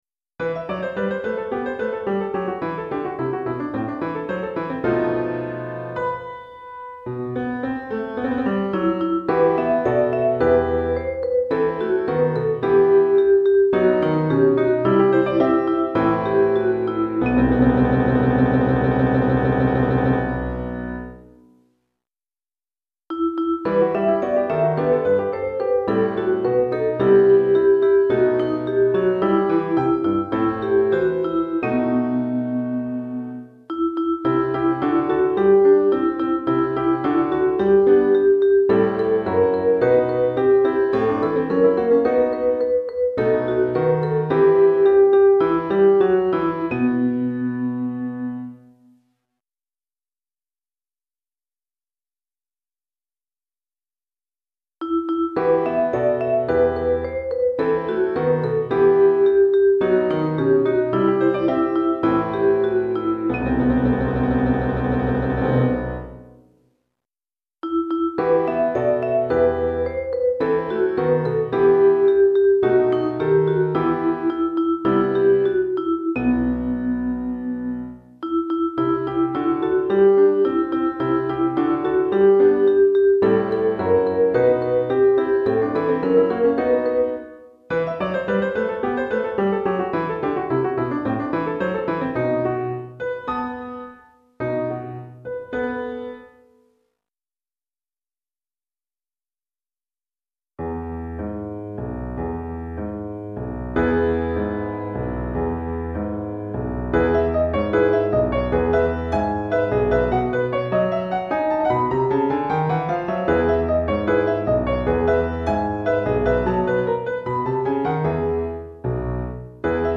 Chorale d'Enfants (10 à 11 ans) et Piano